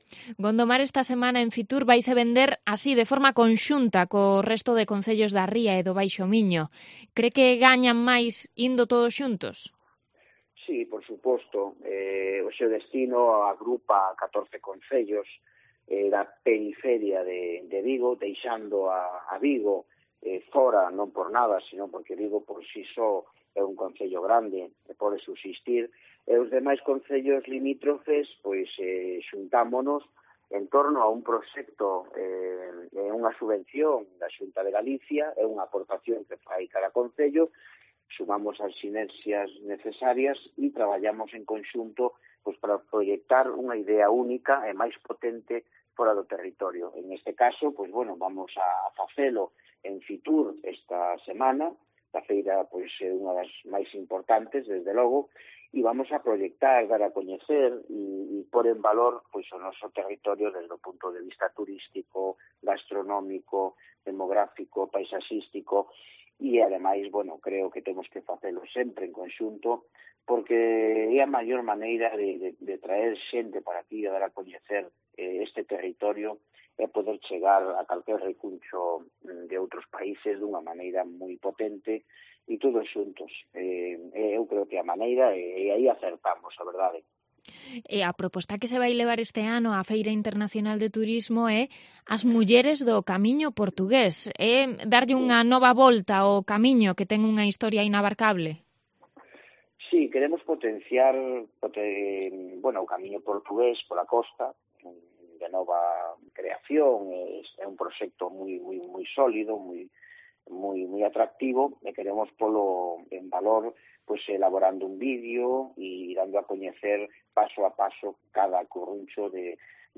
Entrevista a Paco Ferreira, alcalde de Gondomar